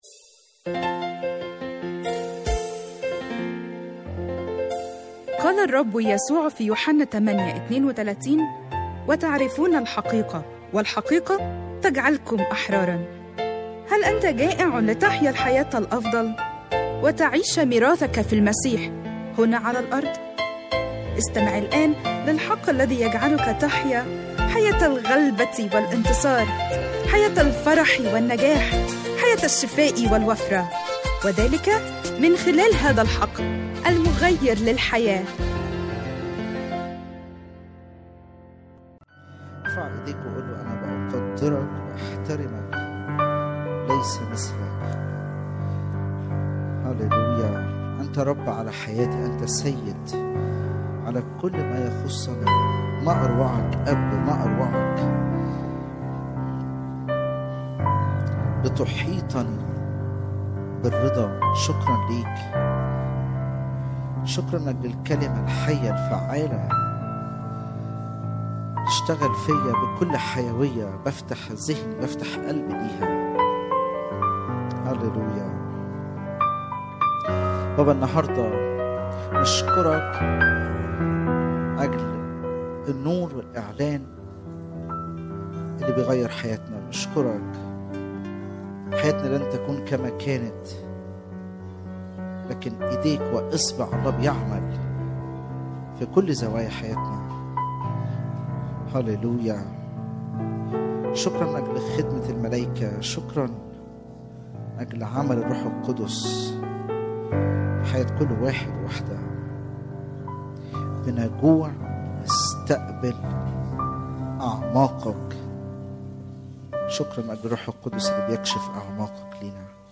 لسماع العظة على الساوند كلاود أضغط هنا